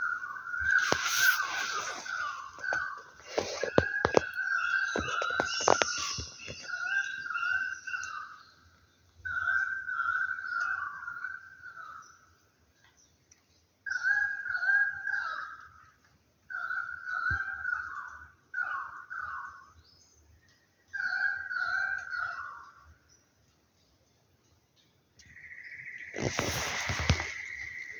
Currawong Pálido (Strepera graculina)
Localidad o área protegida: Lamington National Park
Condición: Silvestre
Certeza: Vocalización Grabada